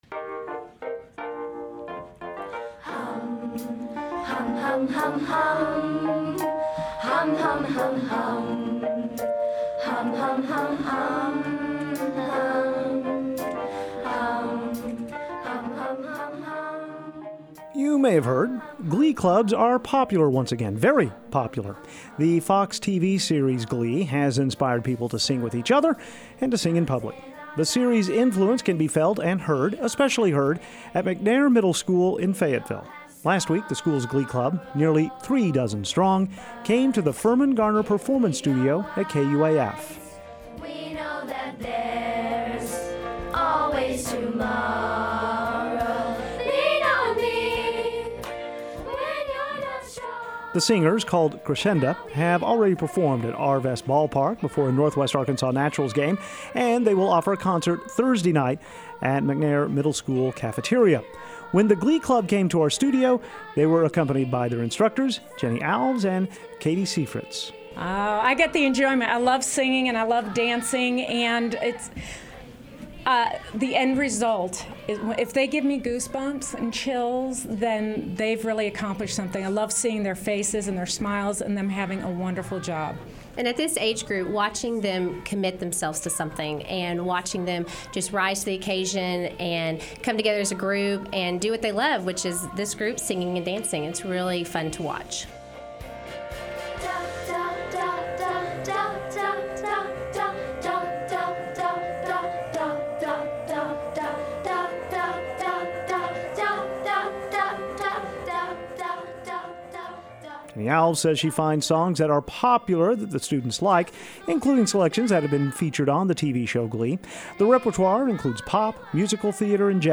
Glee Club
The McNair Elementary Glee Club recently stopped by the Firmin-Garner Performance Studio to sing a few songs.
Glee_Club.mp3